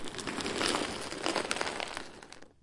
布料的沙沙声3
描述：布料沙沙
标签： 运动沙沙 弗利
声道立体声